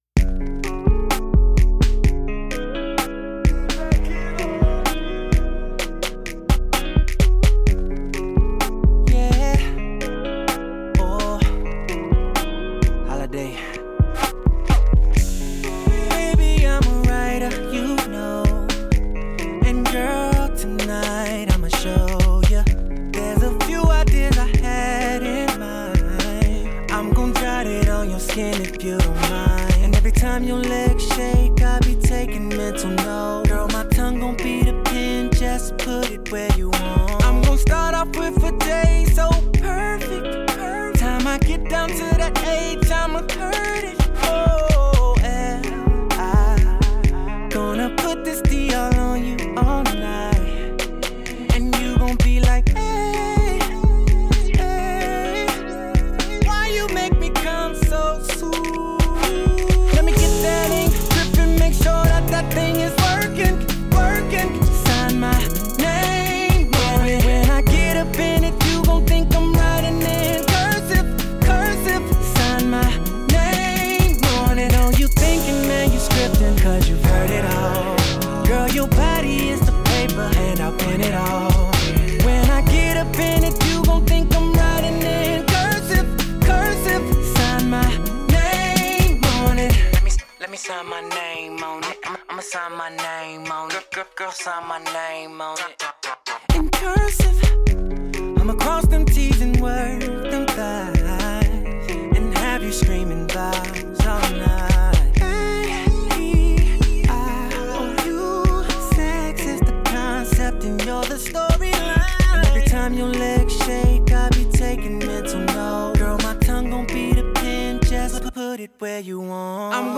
R&B singer